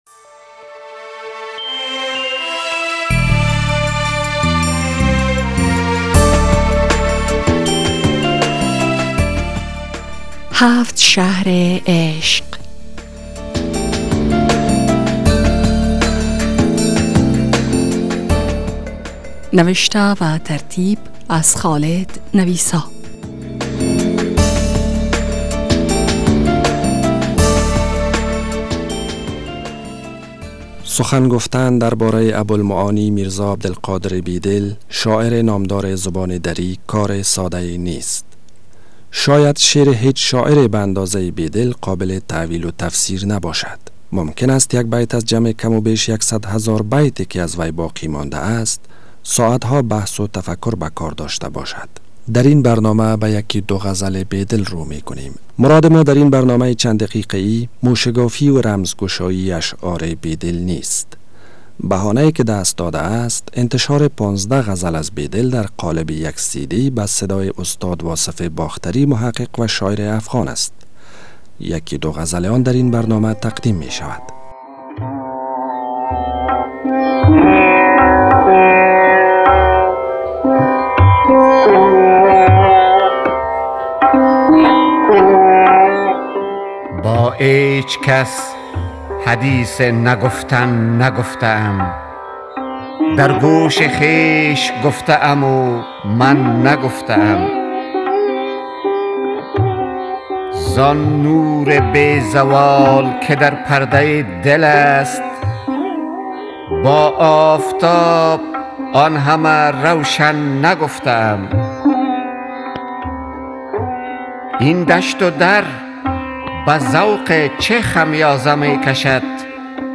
غزل های از ابوالمعانی به صدای استاد واصف باختری